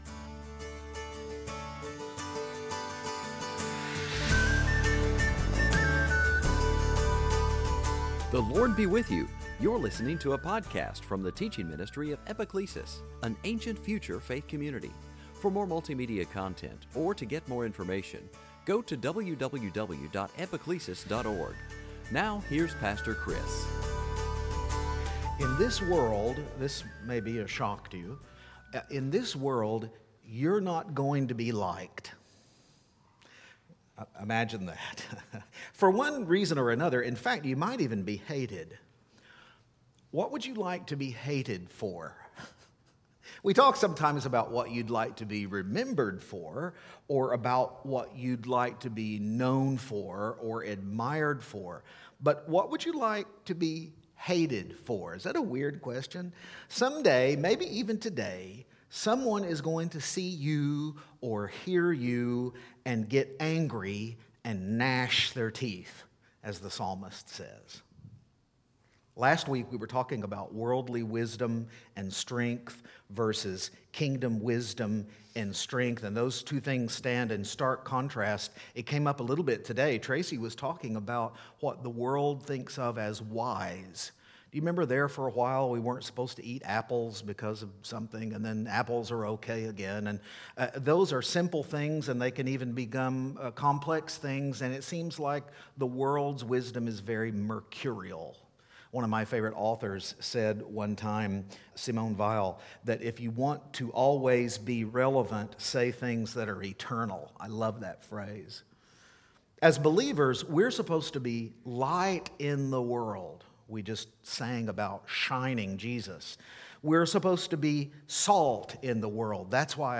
Series: Sunday Teaching
Matt 5:13-16 Service Type: Epiphany In this world